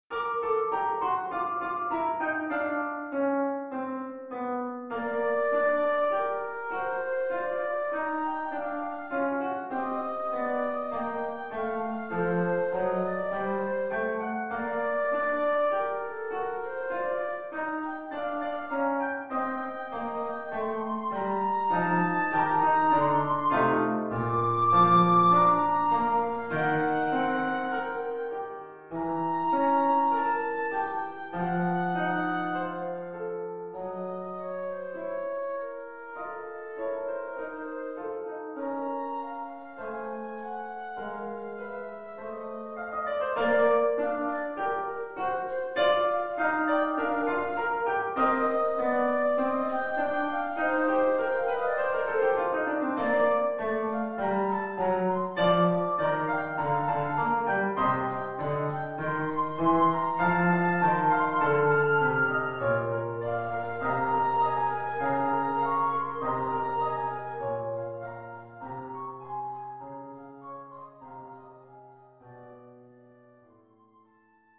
Oeuvre pour flûte et piano.